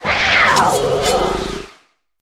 Cri de Baojian dans Pokémon HOME.